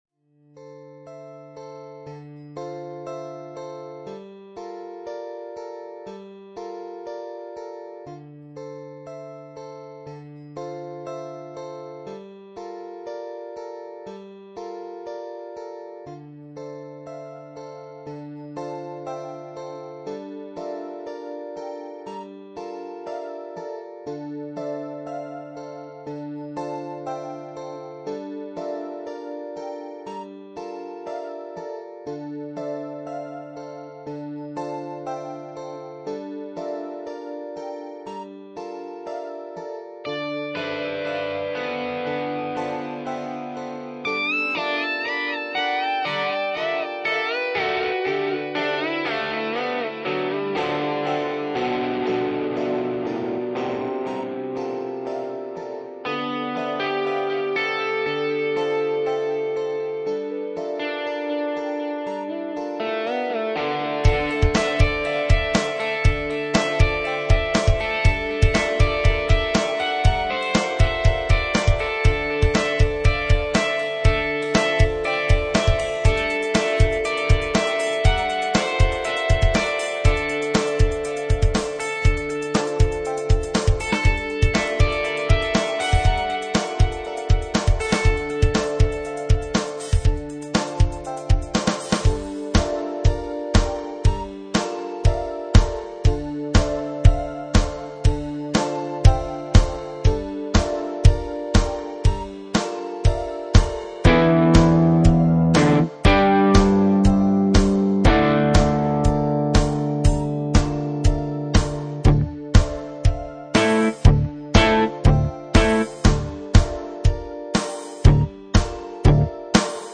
Music
Synth percussion & electric track, once again have fun :)